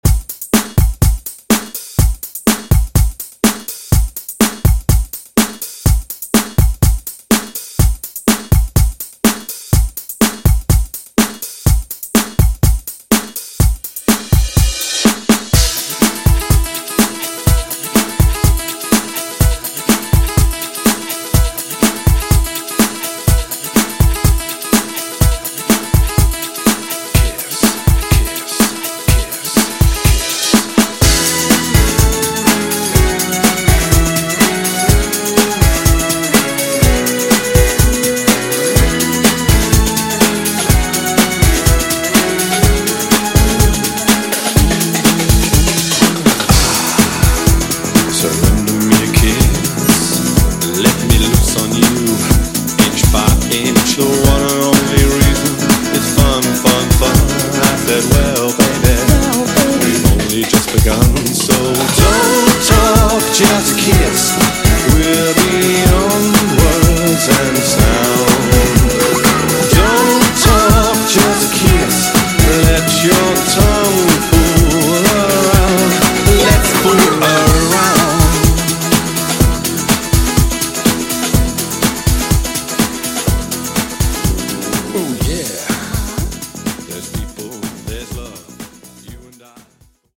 90s Rock ReDrum)Date Added